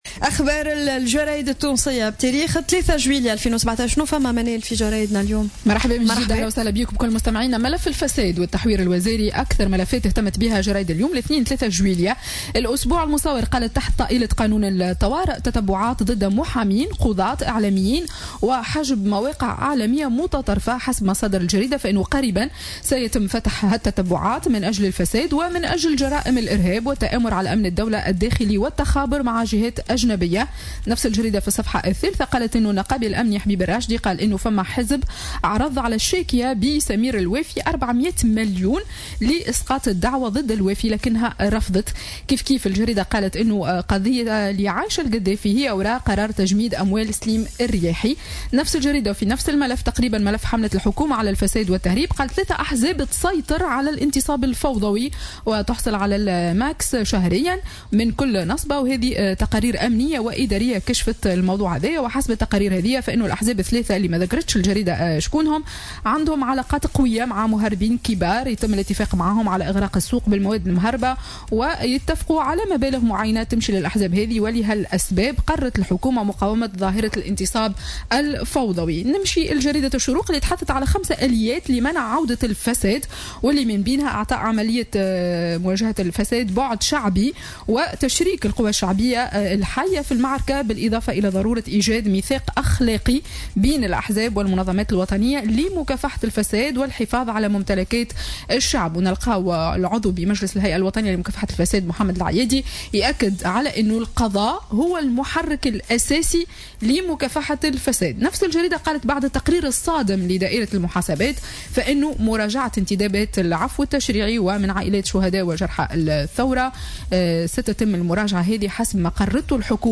Revue de presse 03/07/2017 à 11:31